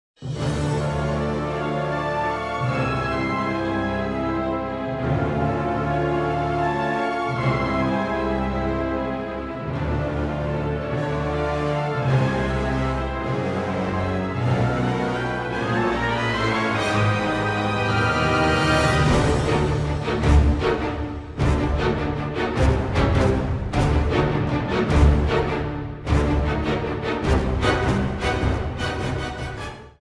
soundtrack
Trimmed to 30 seconds, with a fade out effect